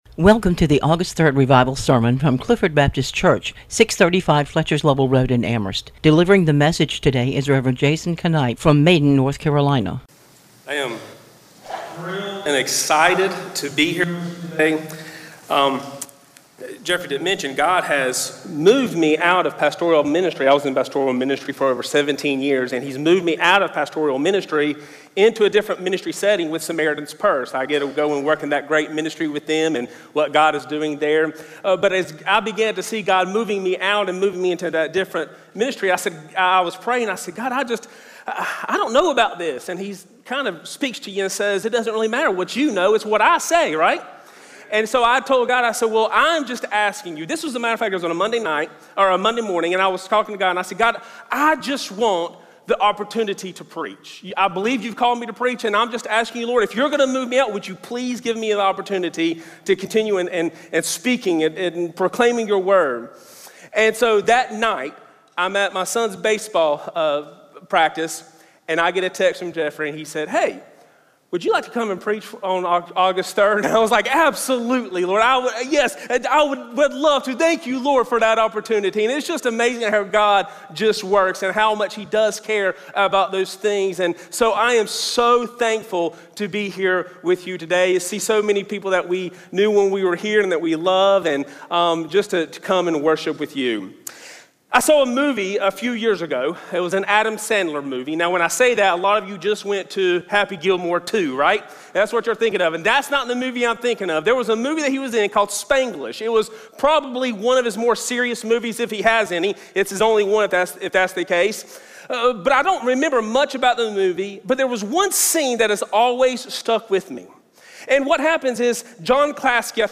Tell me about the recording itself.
Clifford Baptist Revival Service Sunday Morning